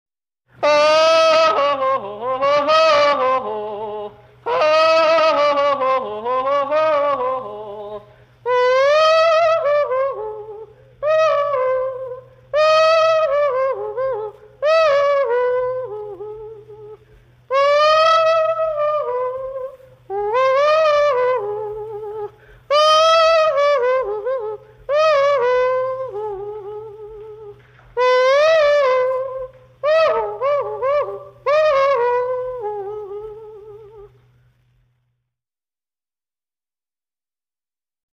Appels à travers champs